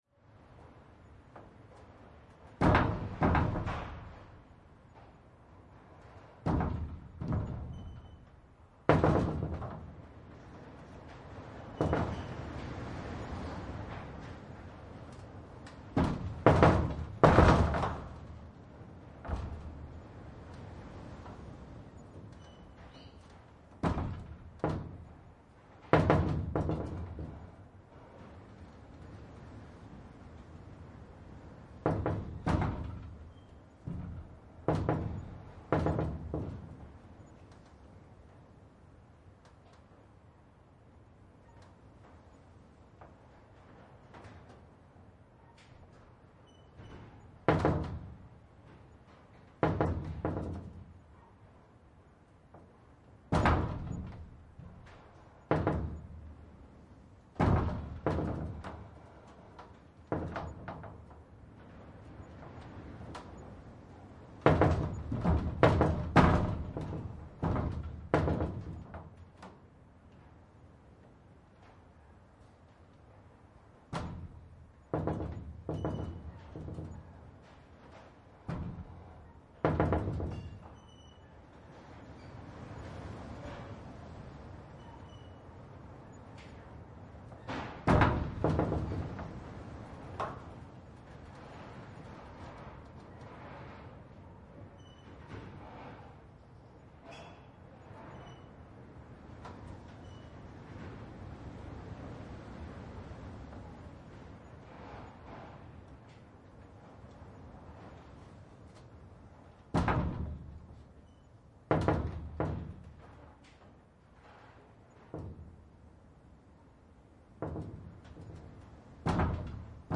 阿根廷，巴塔哥尼亚 " 被拍打的金属门
描述：被打的金属门用不同的强度，风在背景中。